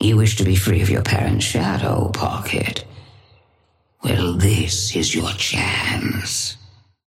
Patron_female_ally_synth_start_01.mp3